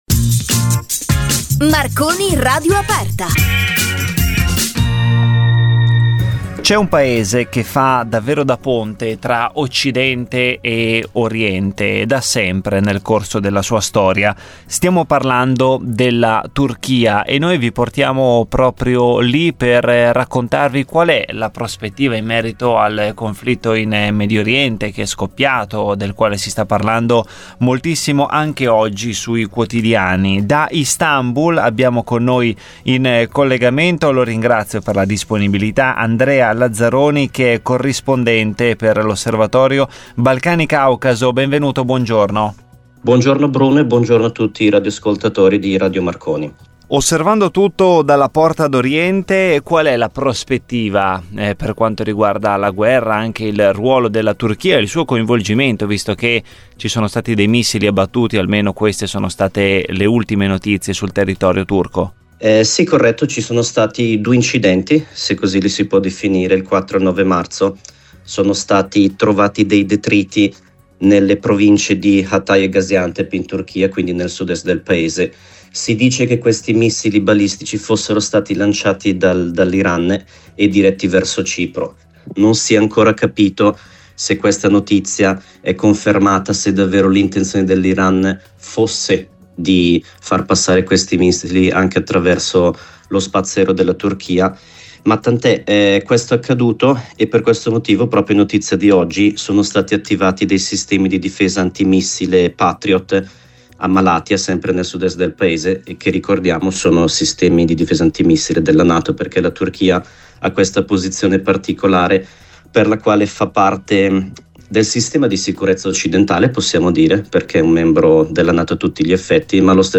alla trasmissione “Marconi Radio aperta” (11 marzo 2026)